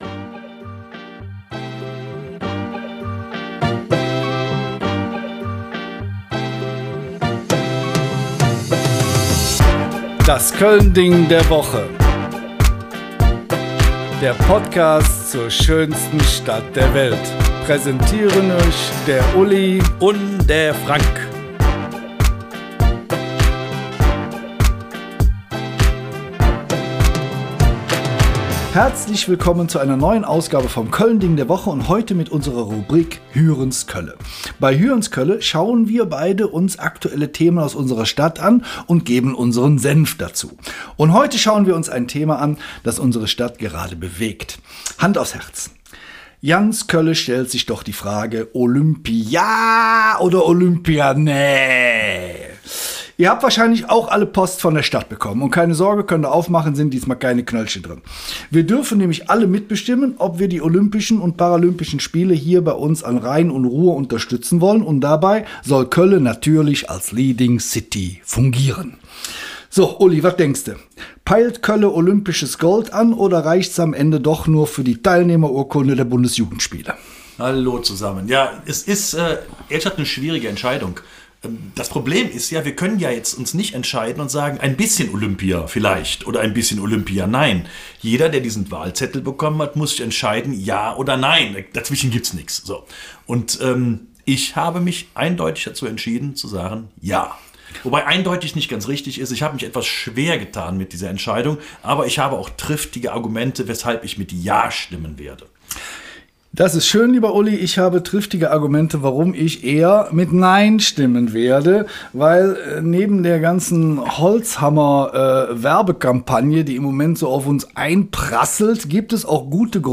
Stellt euch das eher wie ein Gespräch zwischen Freunden an der Theke vor.